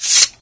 Slash12.ogg